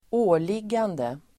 Ladda ner uttalet
åliggande substantiv, obligation, duty Uttal: [²'å:lig:ande] Böjningar: åliggandet, åligganden, åliggandena Synonymer: plikt Definition: plikt, skyldighet Exempel: han skötte inte sina åligganden (he failed to meet his obligations)